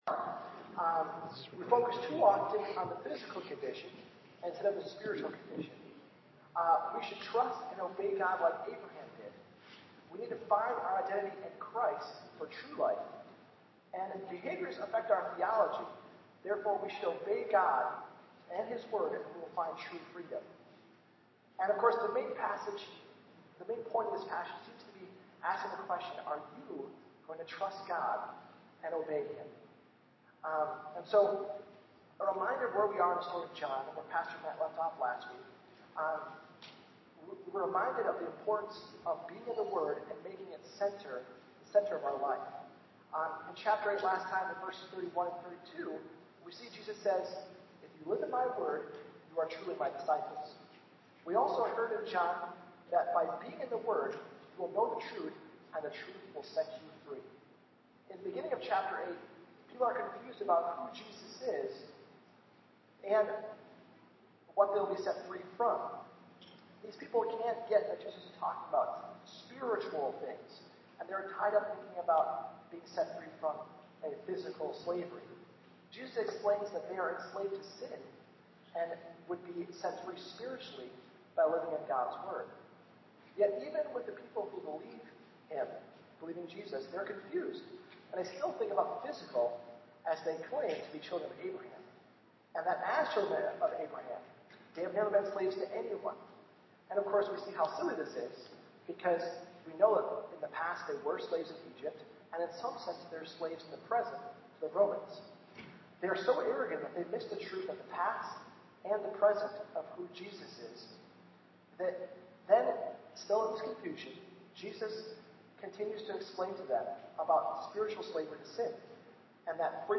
Sermon
John 9:1-12 Service Type: Sunday Worship